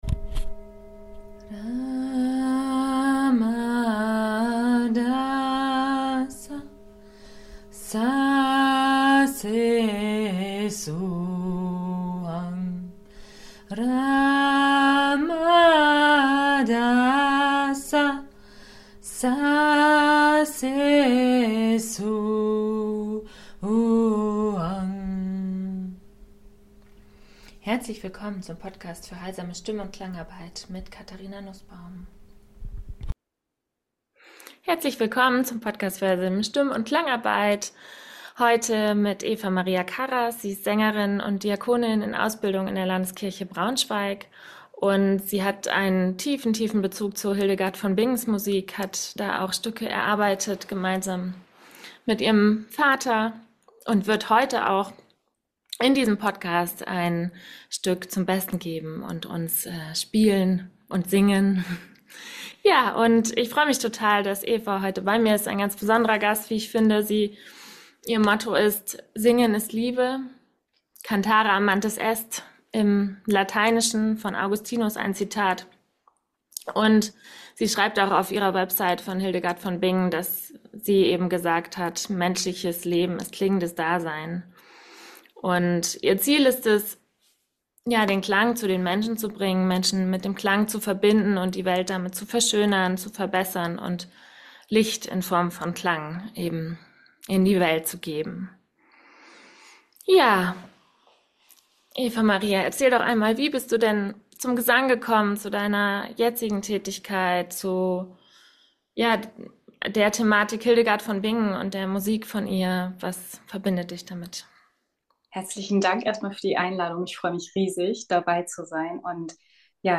Über Hildegards Musik und ihre tief berührende und heilende Wirkung erzählt sie dir in dieser Podcastfolge. Zudem spielt sie ein Lied über die Liebe ein. Das Lied ist nicht ganz zu hören, weil meine technische Ausstattung es noch nicht möglich macht, dass es durchgehend richtig schön klingt.